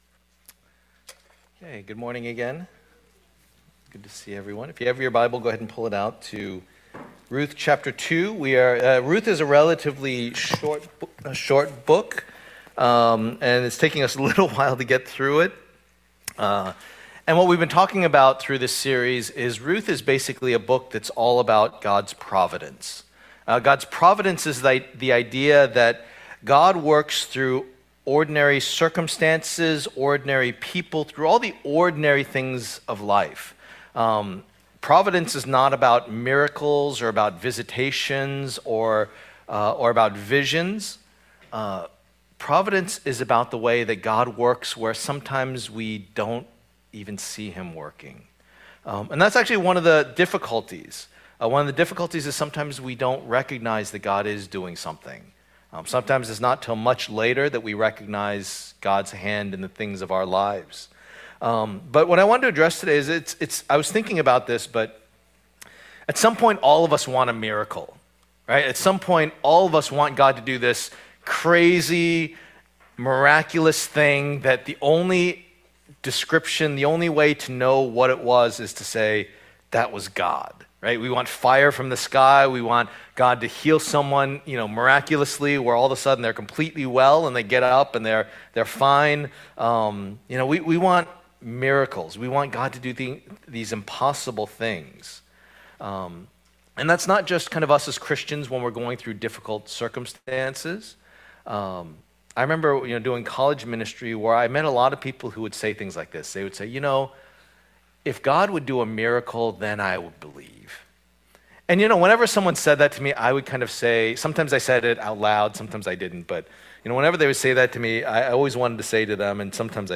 Passage: Ruth 3:1-18 Service Type: Lord's Day